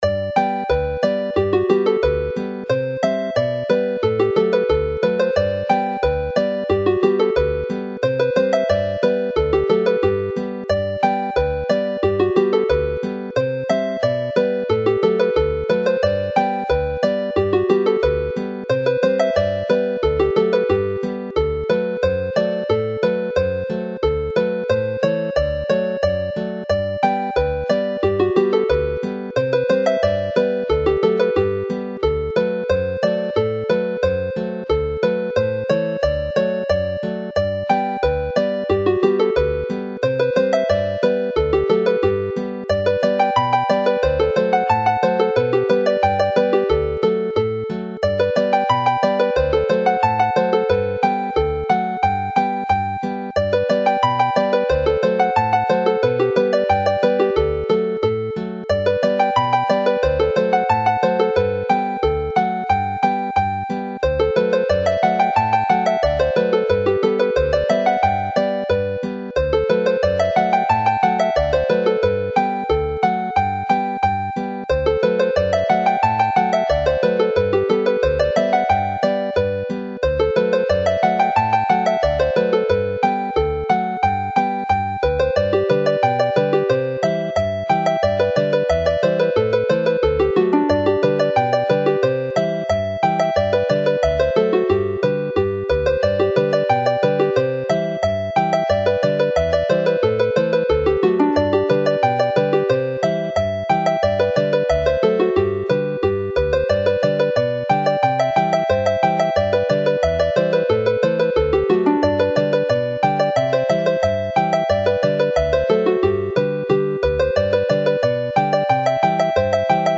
Chwareir y set fel arfer yn gyflym, gydag ysbryd, gan ddechrau'n gymharol tawel cyn rhedeg gyda Llanthony a Gwrecsam.
The Wrexham hornpipe is played as a polca as written, rather than as a hornpipe.
This set is normally played with pace and spirit, starting fairly gently with Rhydycar and then taking off with Llanthony and Wrexham.